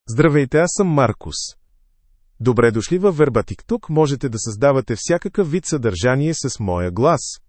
Marcus — Male Bulgarian (Bulgaria) AI Voice | TTS, Voice Cloning & Video | Verbatik AI
MarcusMale Bulgarian AI voice
Marcus is a male AI voice for Bulgarian (Bulgaria).
Voice sample
Marcus delivers clear pronunciation with authentic Bulgaria Bulgarian intonation, making your content sound professionally produced.